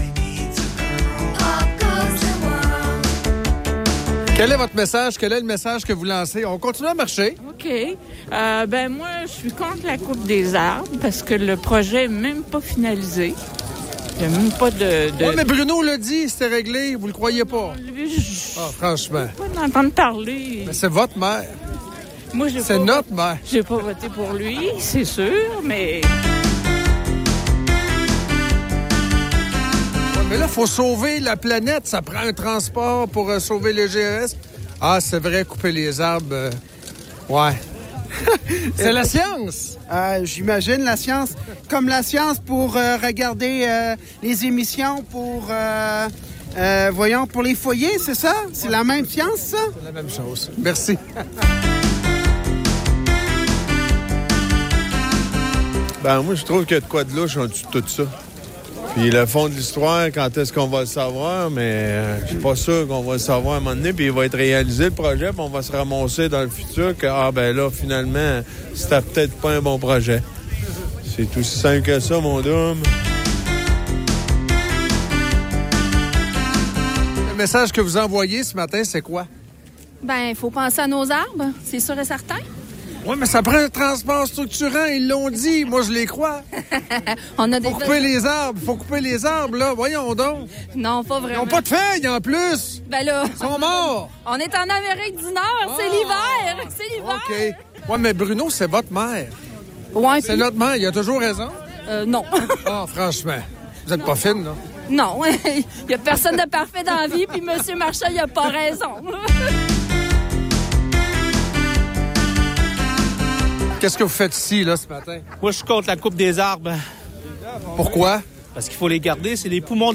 VOX POP